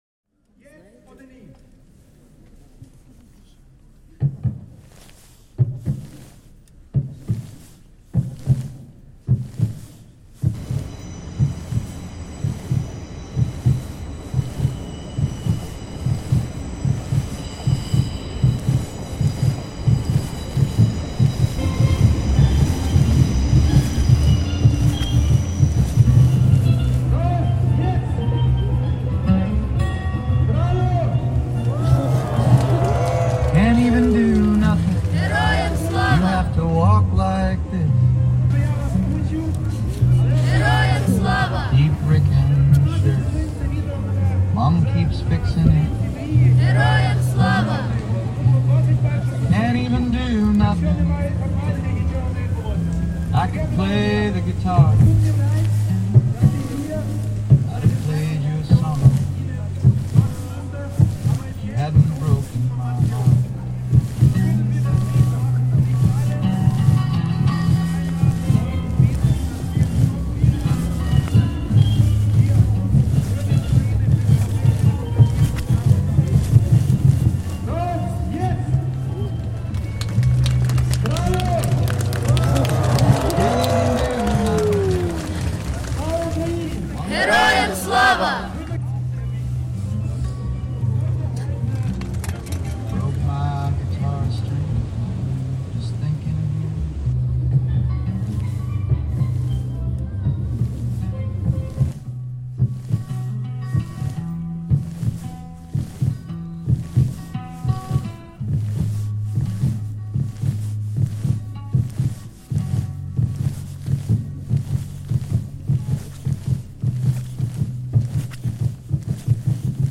Our piece is inspired by a ‘March of Light’, a field recording from Austria. The rhythmic beat resembles a heartbeat. The voices that call out seem to be either a threat or a cry for help.
The original piece has more darkness than light, and we worked to combine this with the tinkling melody of a steel band, which we recorded in London’s Angel Islington. Woven around this is our field recording of the sounds of Crossbones – a medieval garden of remembrance for outcasts in London’s Borough Market area.
Our sound piece is plaintive, and the incessant beat feels as if it is chasing us – a fitting soundtrack to the end of 2025 – a year where it often felt it was hard to ‘even do nothing’.